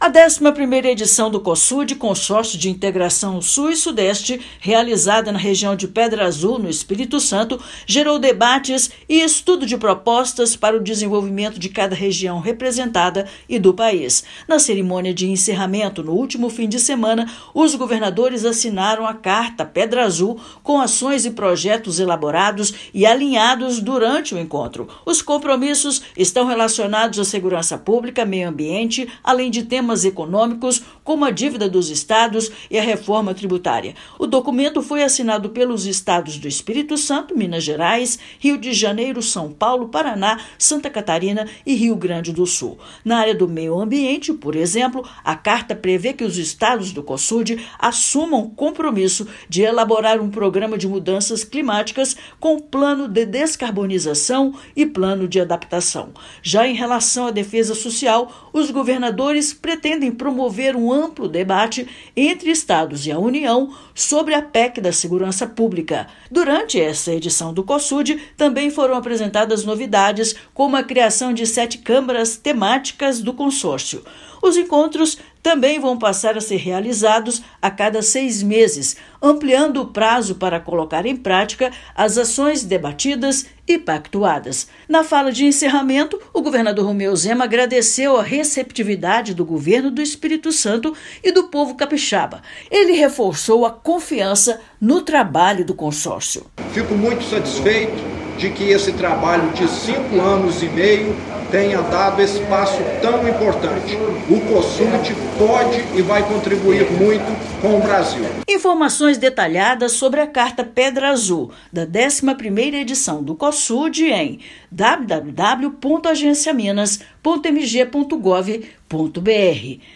Temas como reforma tributária e dívida dos Estados também fizeram parte das discussões desta edição. Ouça matéria de rádio.